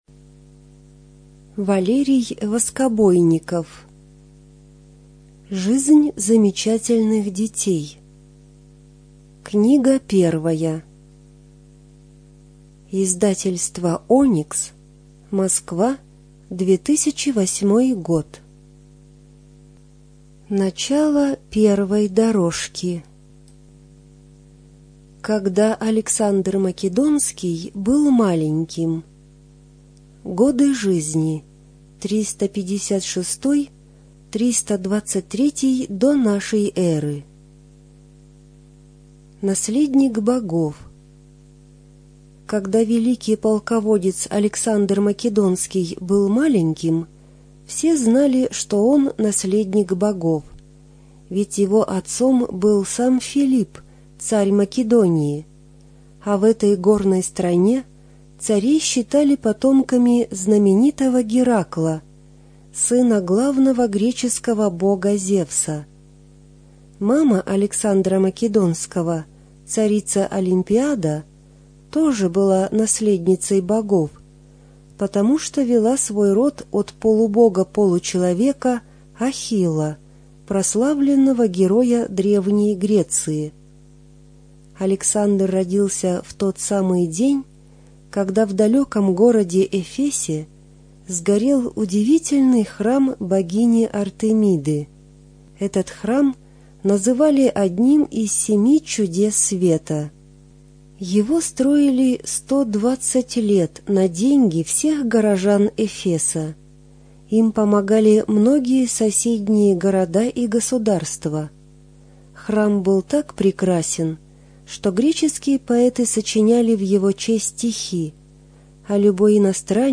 Студия звукозаписиБелгородская областная библиотека для слепых имени Василия Яковлевича Ерошенко